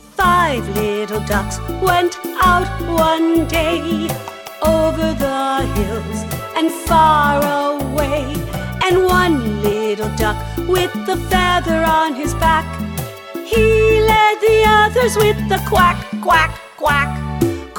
Genre: Children's Music.